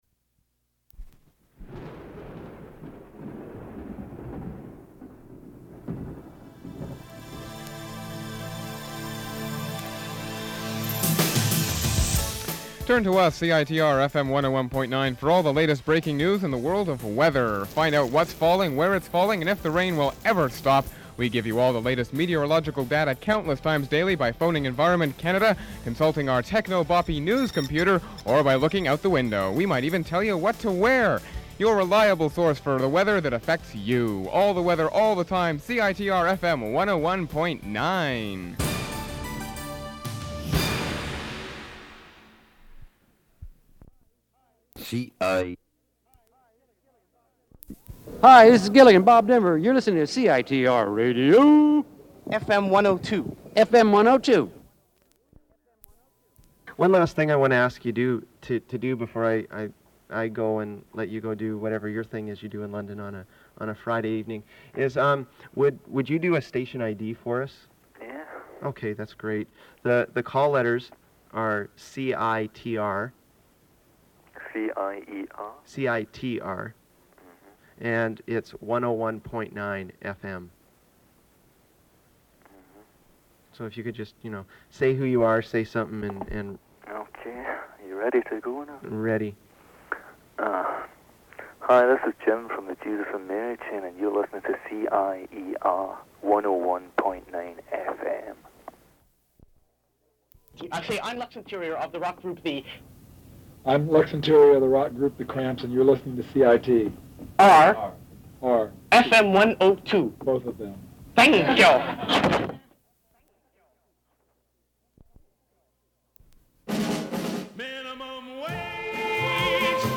1990/91 archives station I.D.'s